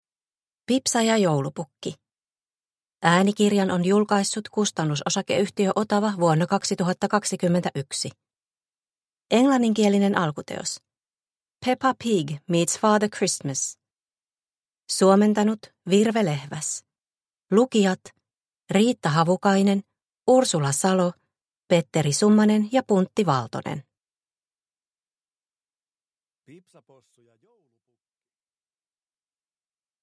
Pipsa Possu ja joulupukki – Ljudbok – Laddas ner